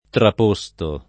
traporre [ trap 1 rre ]